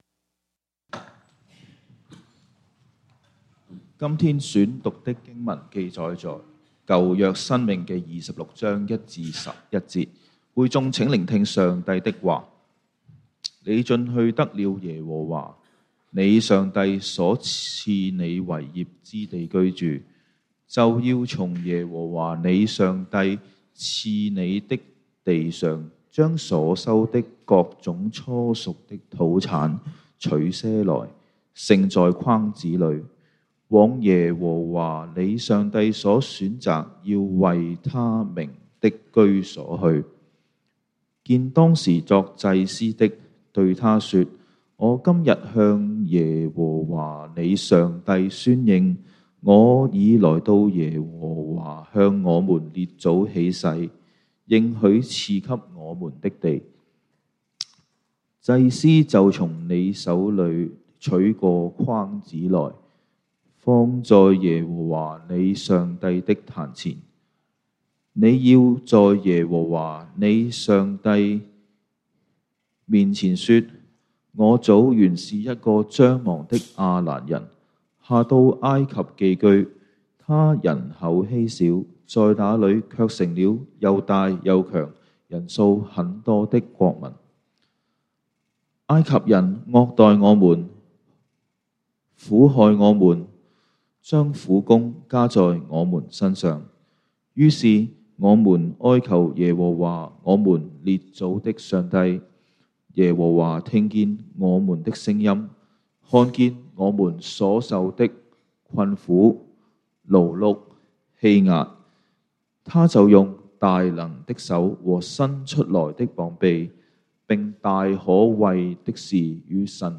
3/23/2025 講道經文： 申命記 Deuteronomy 26:1-11 本週箴言：歷代志上 1 Chronicles 29:14 「我算甚麼，我的民算甚麼，竟能如此樂意奉獻？